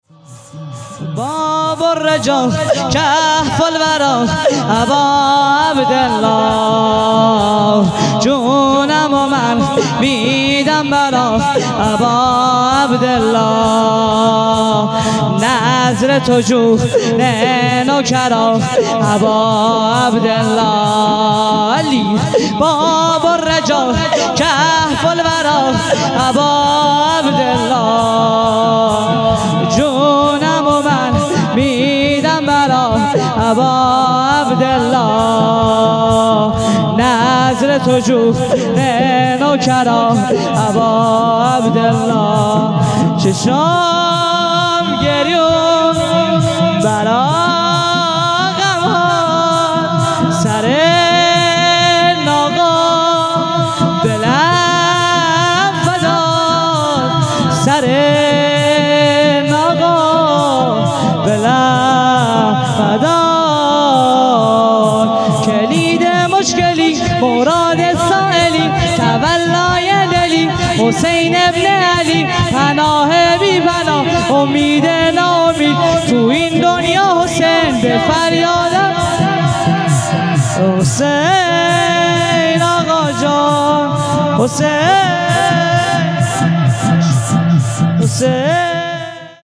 شور | باب الرجاء